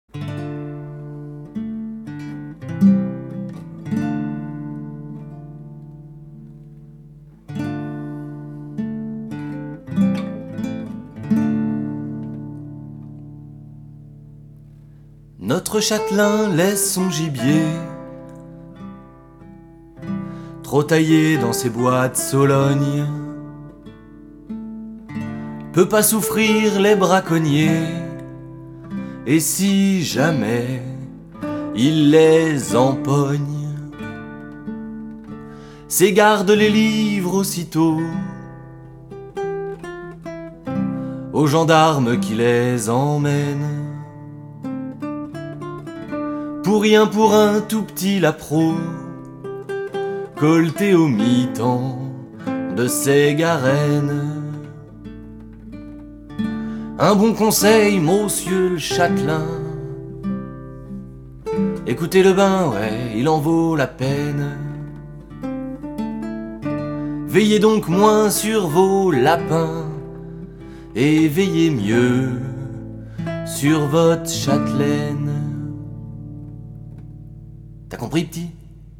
Une fable musicale écolo romantique
une fable écolo-romantico-pédagogique contée et chantée dans laquelle on apprendra le mode de reproduction de certains papillons, ce qu’est la chaîne alimentaire, et où vont se croiser un papillon, (une azurée des mouillères), un thon (qui est en fait une alose feinte, Alosa Fallax en latin, mais fi des détails…), une assemblée de grands sages de la nature élue au suffrage naturel direct, un caillou corse, des braconniers chansonniers, un rastaquouère qui pue des pieds, des poissons en grève et, espérons-le, le Grand Dépollueur et une azurée des mouillères métamorphosée en la plus belle fille qui jamais ne fut.